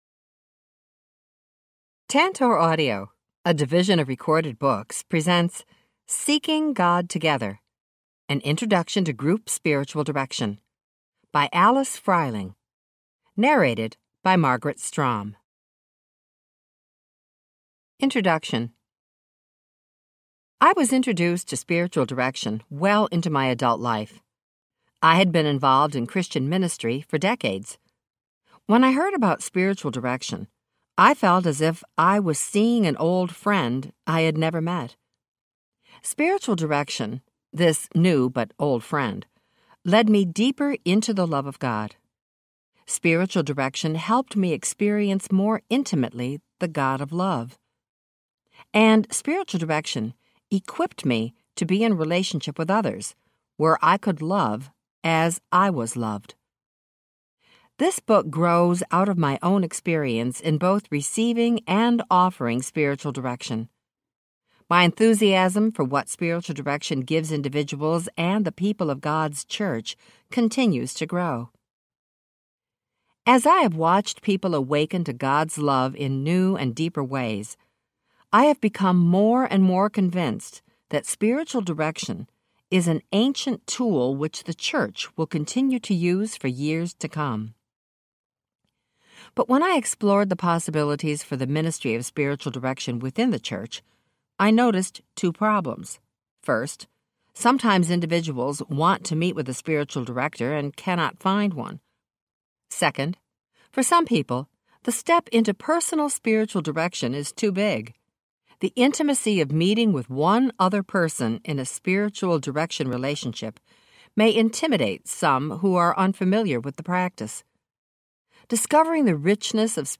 Seeking God Together Audiobook
5.5 Hrs. – Unabridged